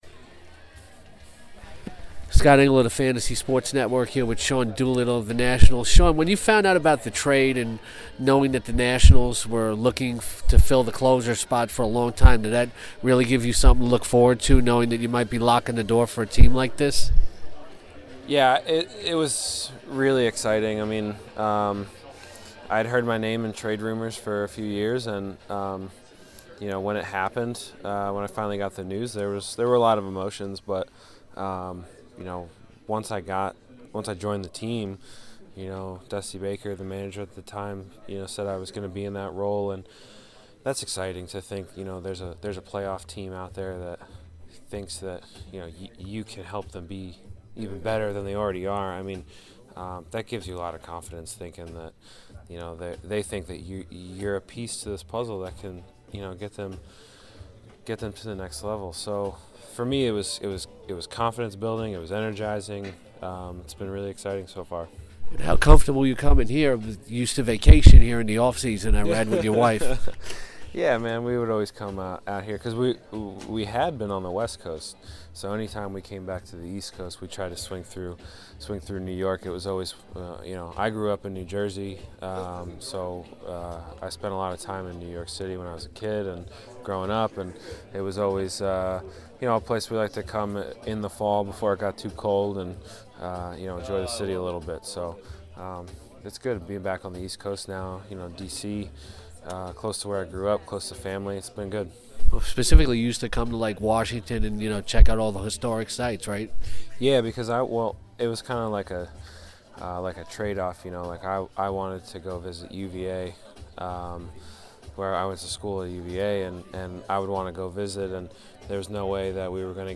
n this wide-ranging interview with Nationals closer Sean Doolittle, the fireman talks everything from the closer's mentality to the new Solo movie.